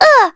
Maria_L-Vox_Damage_03.wav